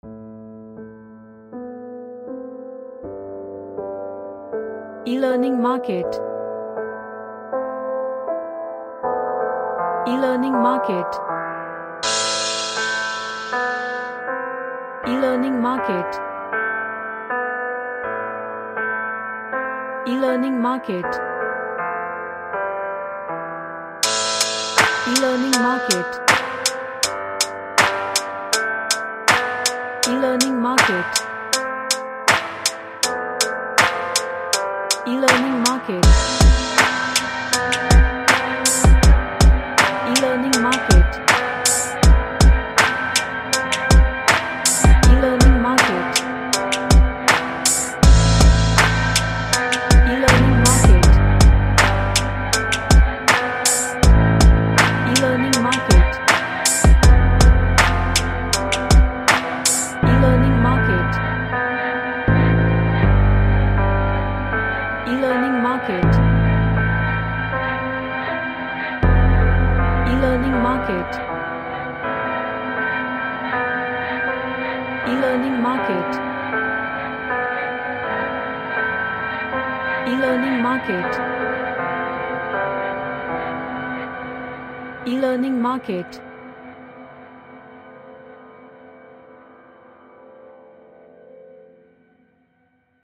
A dark lofi track
Dark / Somber